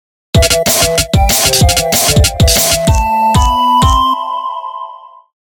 ラジオ風